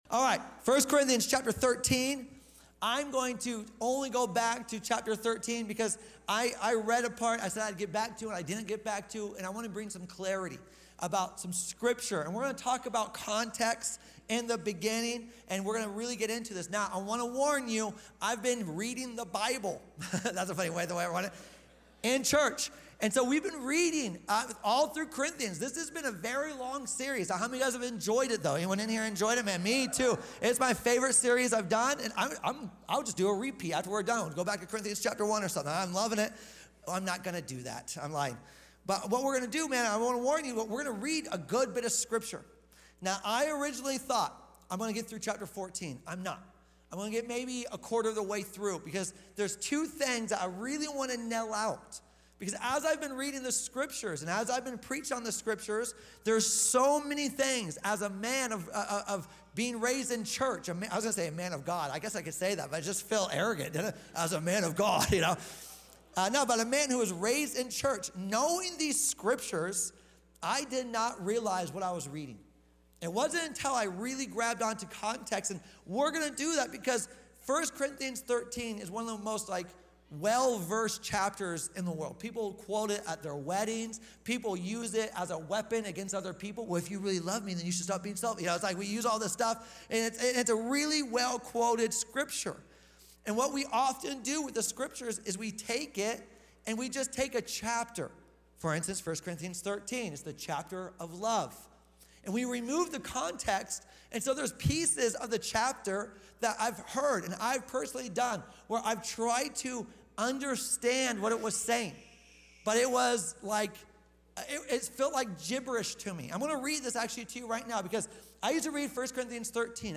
Sunday Livestream